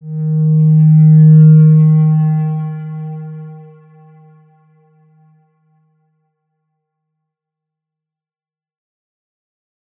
X_Windwistle-D#2-pp.wav